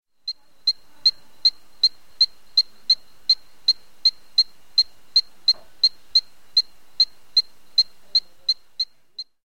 20 Epipedobates Pictus.mp3